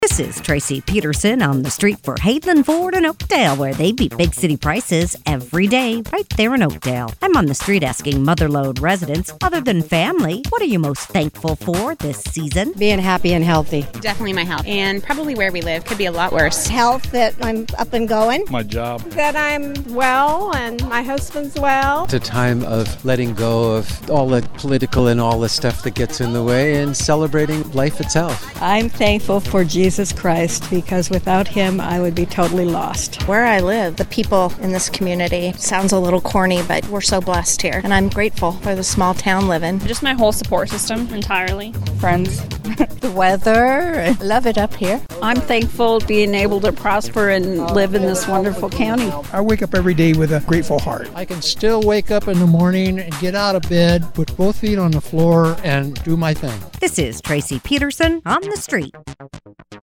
asks Mother Lode residents, “Other than family, what are you most thankful for this season?”